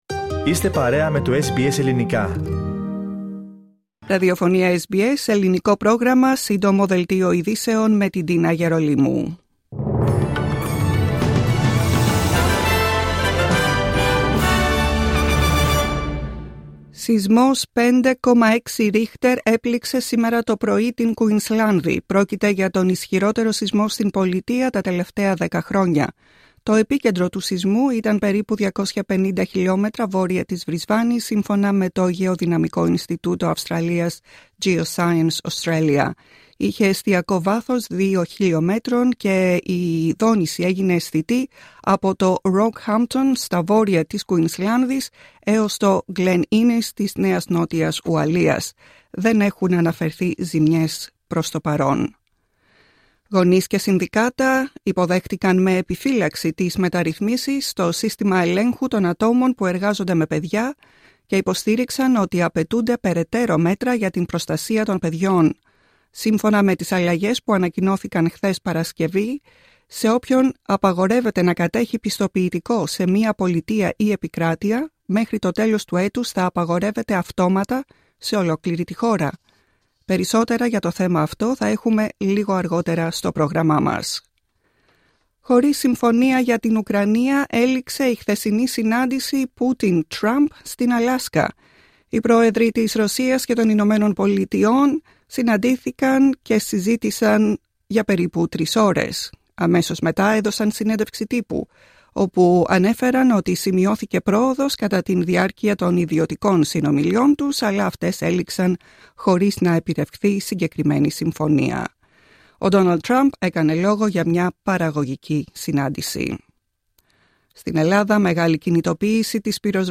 Σύντομο δελτίο ειδήσεων απ΄το Ελληνικό Πρόγραμμα της SBS.